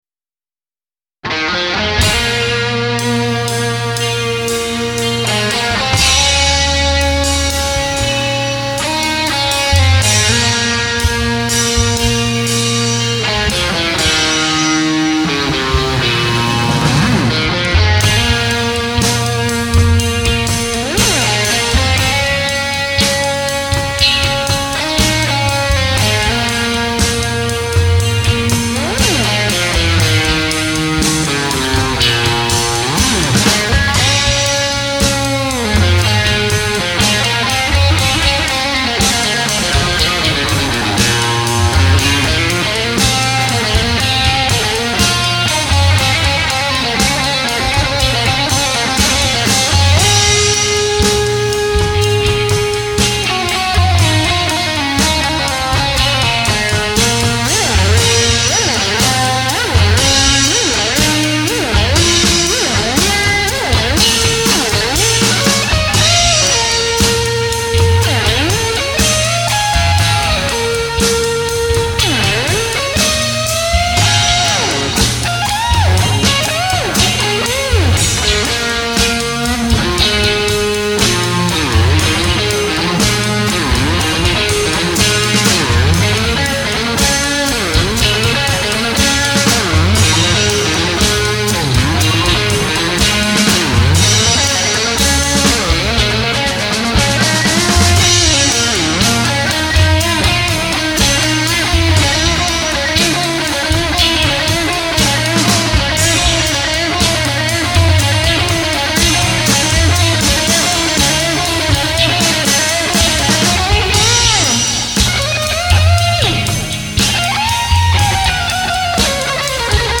Guiter
Bass